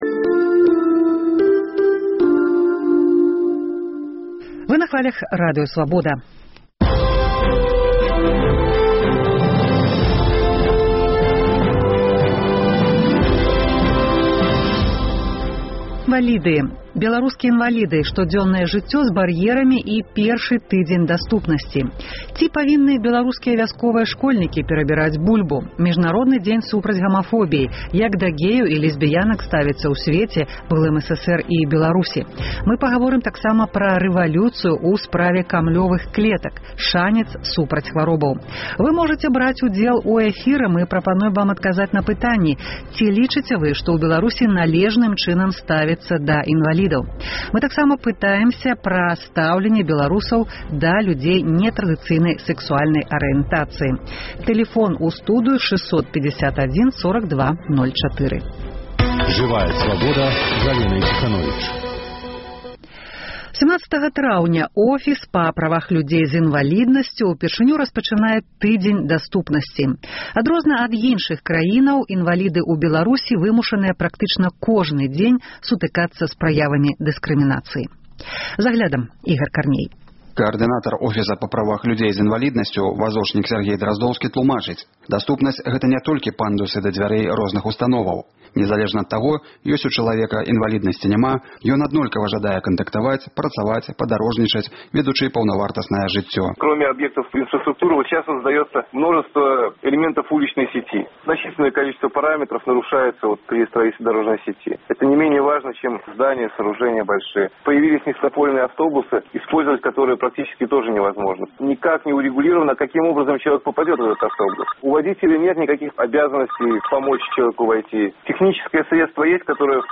Рэвалюцыя ў справе камлёвых клетак — шанец супраць хваробаў. Бярыце ўдзел у абмеркаваньні гэтых тэмаў па тэлефоне і ў сацыяльных сетках.